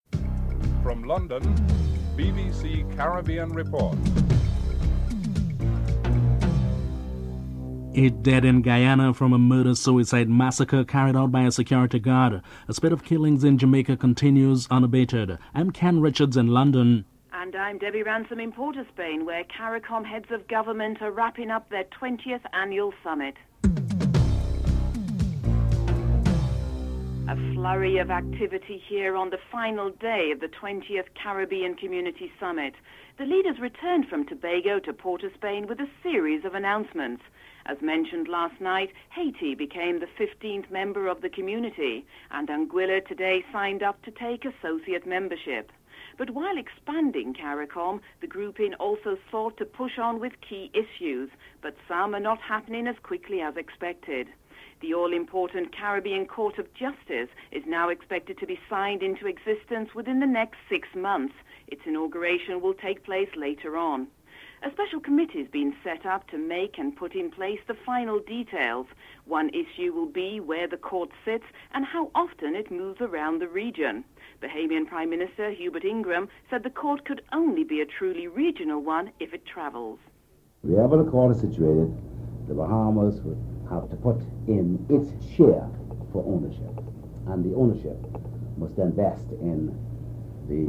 Bahamas Prime Minister Hubert Ingraham discusses ownership of the regional court, availability of funding, and regional acceptance of the Organization of Economic Cooperation and Development (OECD) international regulatory baking standards.
Jamaica Prime Minister PJ Patterson highlights WTO diplomatic representation in Geneva and the need for improved networking for trade negotiations (00:28 – 06:06)